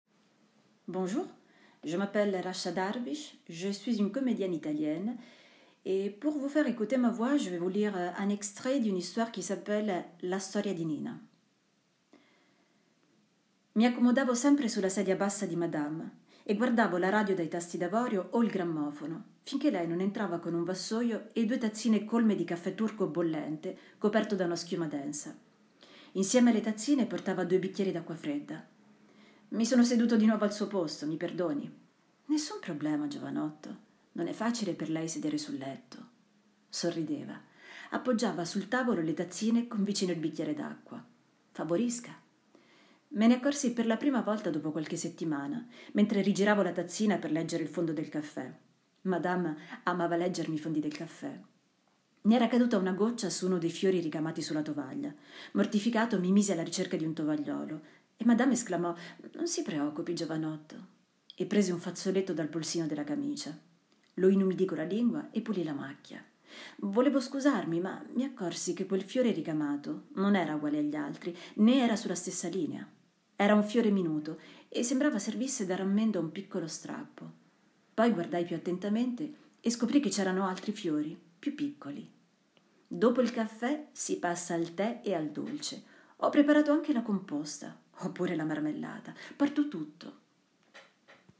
Démo voix en italien
Comédienne Voix off Lectrice